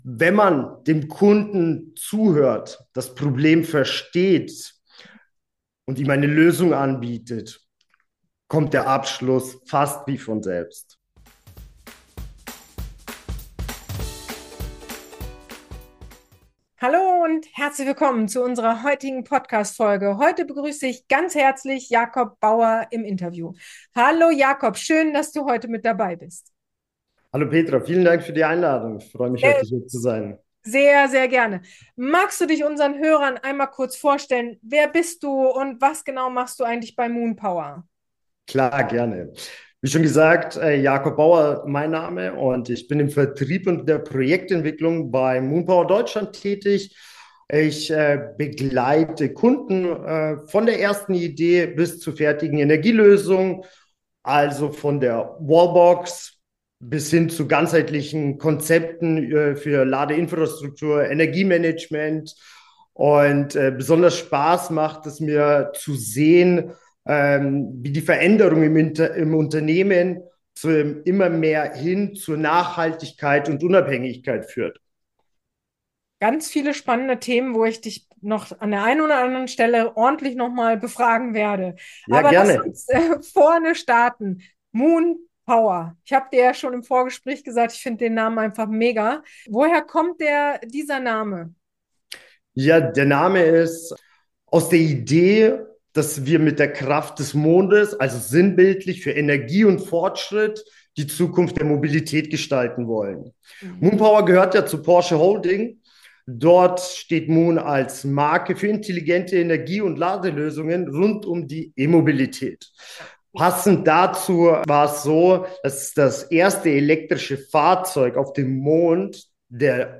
Erfolgreiche B2B-Akquise: Einblicke aus der Praxis – Interview